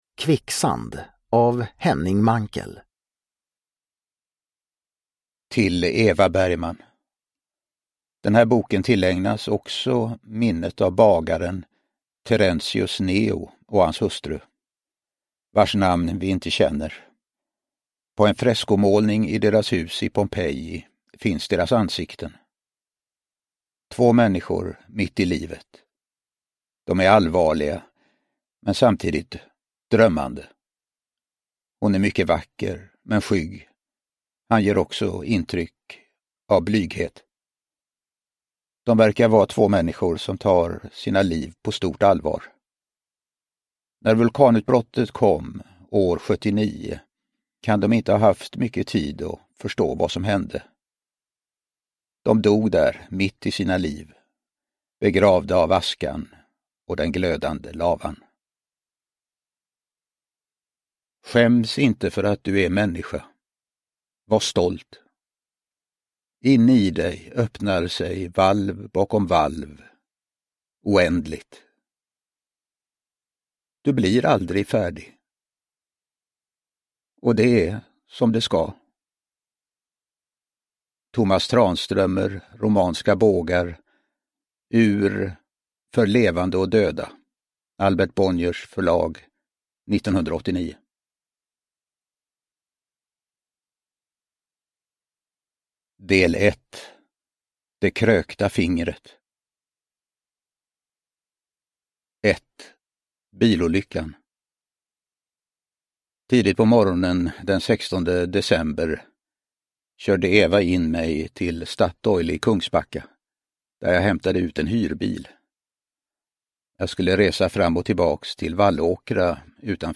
Kvicksand (ljudbok) av Henning Mankell